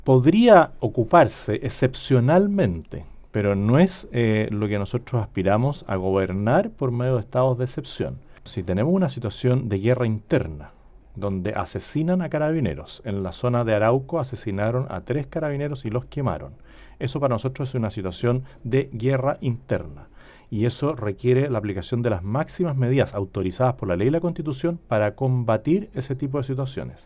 En conversación con La Radio, el abanderado abordó los problemas de violencia en la región, señalando que hay “terrorismo” y que, por lo mismo, la gente no puede dormir en paz y tampoco cosechar sus campos.